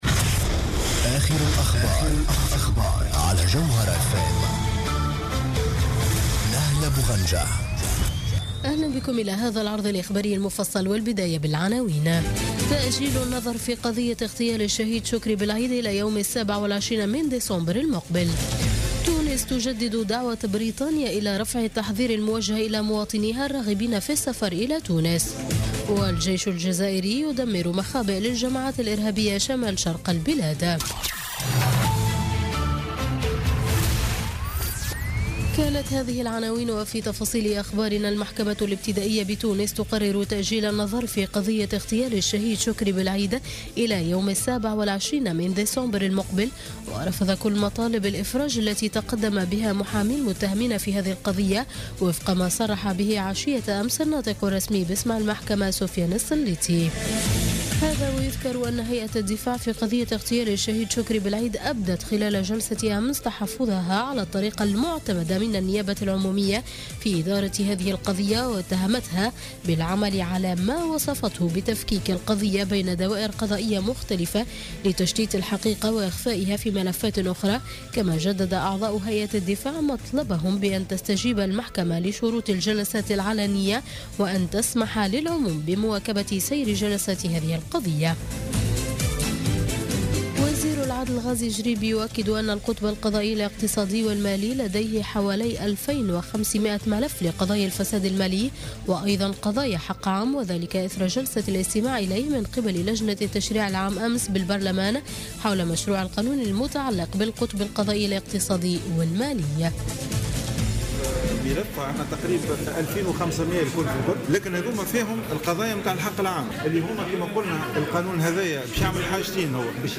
نشرة أخبار منتصف الليل ليوم السبت 29 أكتوبر 2016